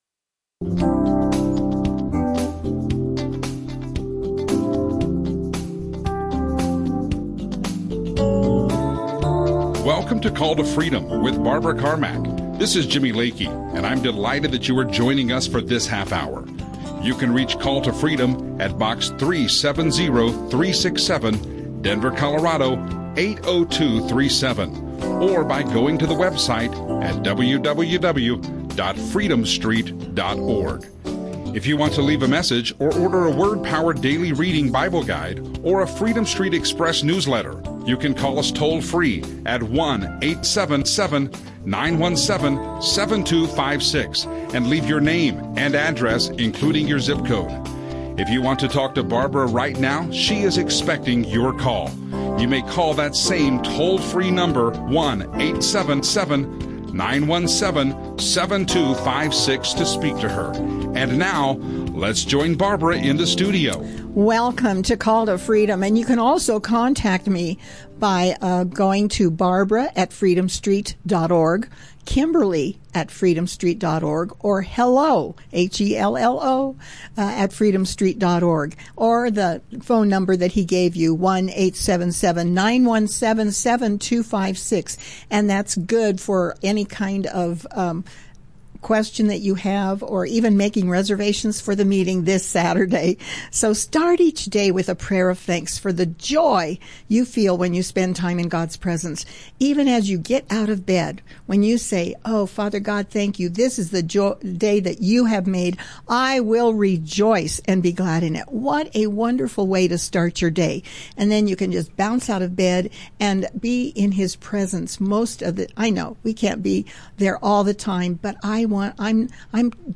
Christian radio
Radio shows